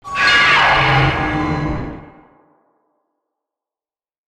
File:Mothra Gemini Imago roar trailer.ogg
Mothra_Gemini_Imago_roar_trailer.ogg